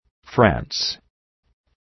Προφορά
{fræns}